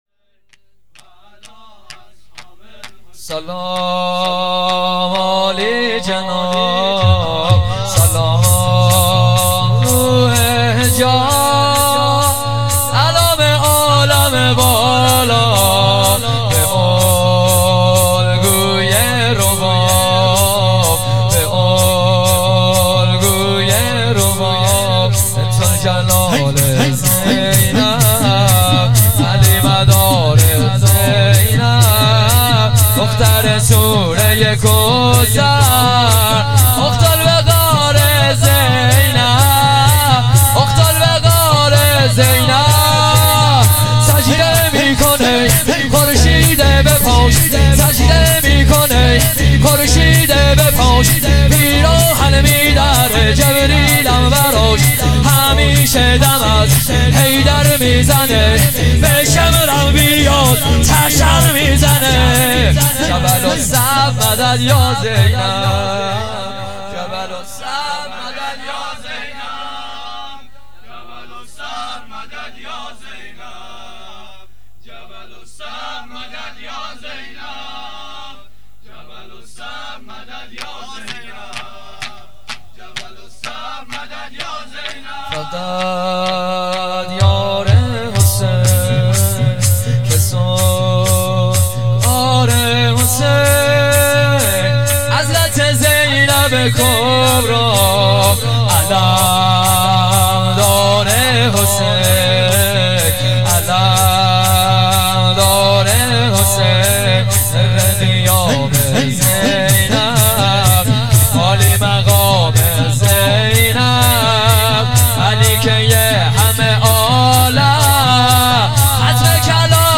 مراسم پیشواز محرم ۹۸.۶.۷